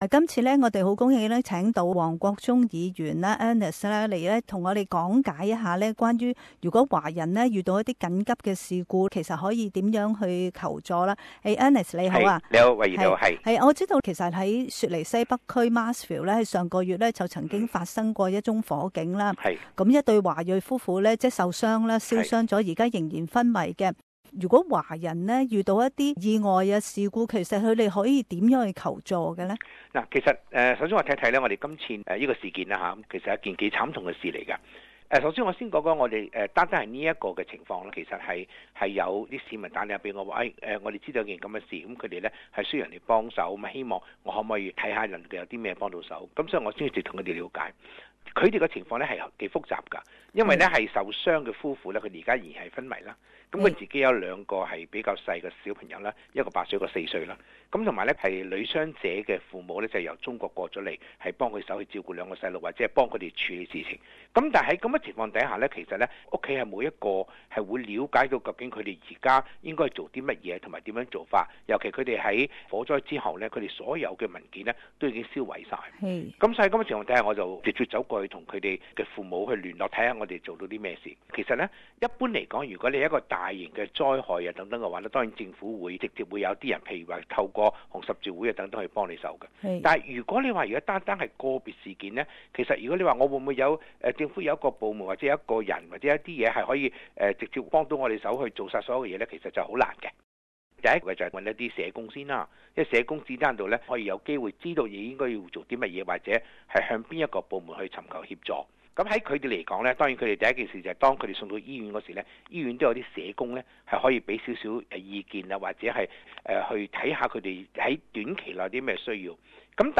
【社区专访】华人遇上事故如何求助